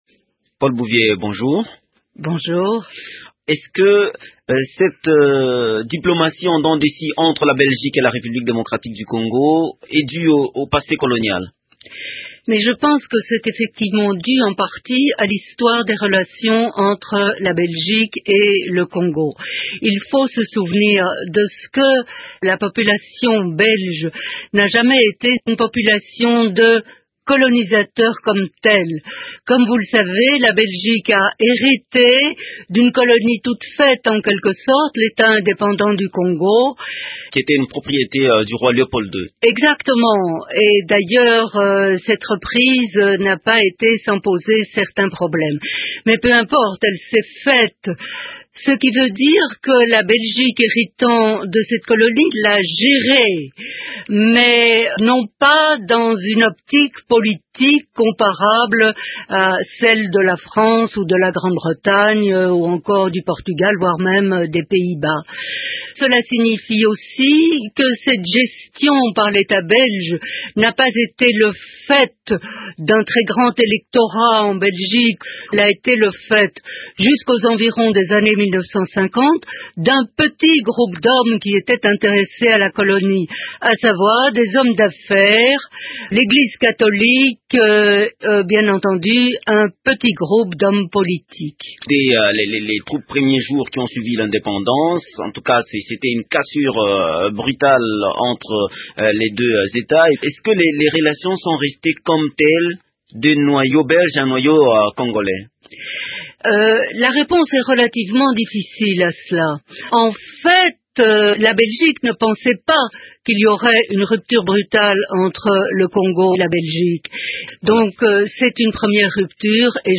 Elle est interrogée